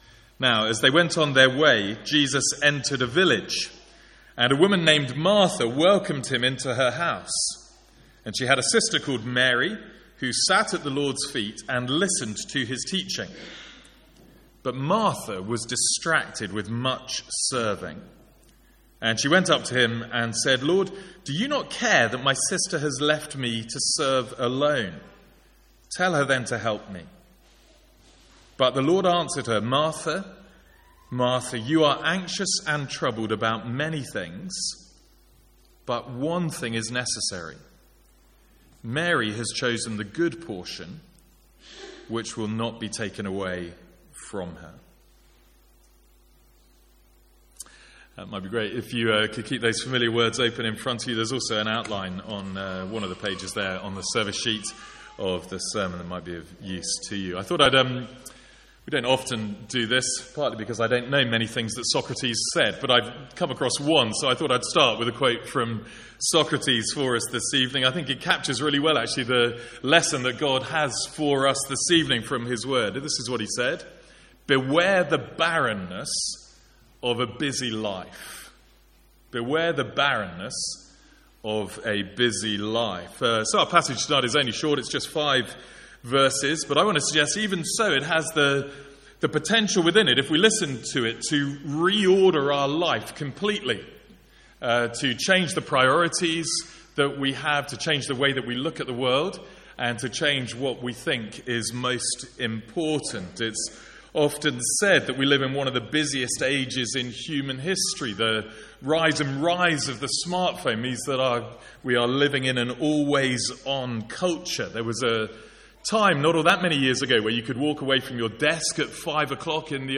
Sermons | St Andrews Free Church
From the Sunday evening series in Luke.